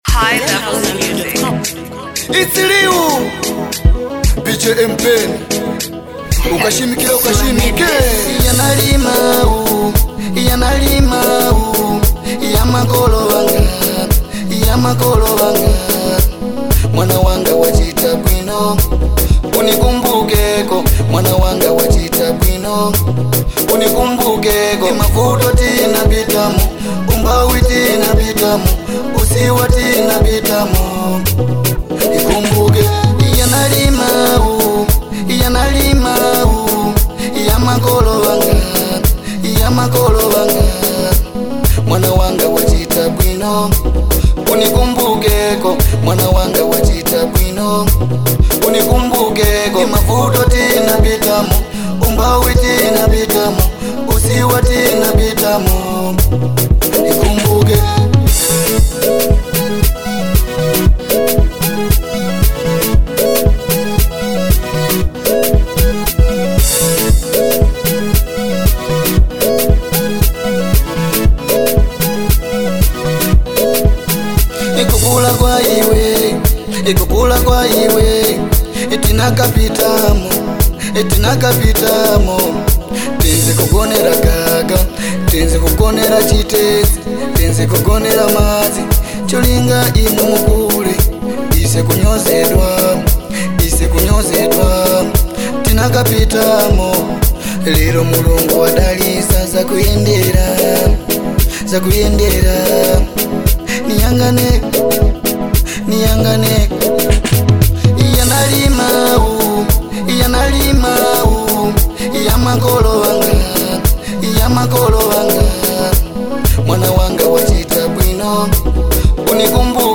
soulful new single